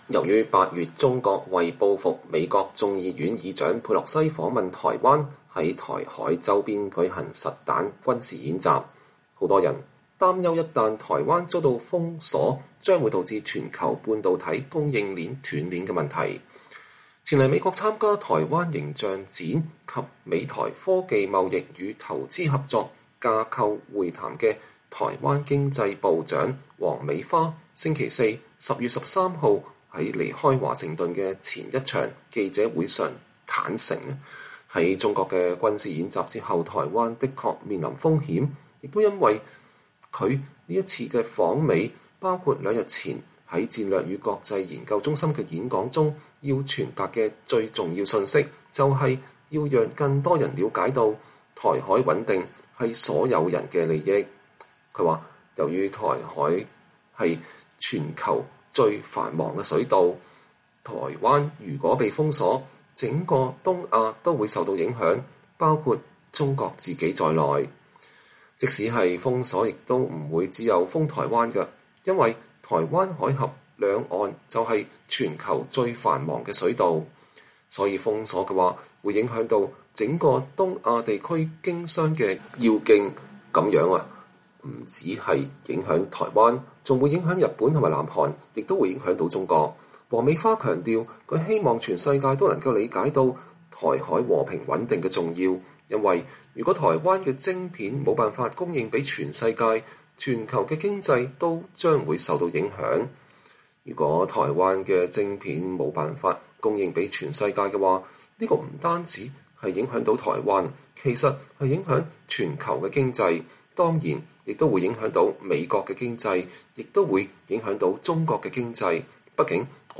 前來美國參加“台灣形象展”及美台“科技貿易與投資合作”架構會談的台灣經濟部長王美花星期四(10月13日)在離開華盛頓前的一場記者會上坦承，在中國的軍事演習後台灣的確面臨風險，這也是為什麼她此次訪美，包括兩天前在戰略與國際研究中心的演講中要傳達的最重要信息，就是要讓更多人了解台海穩定是所有人的利益。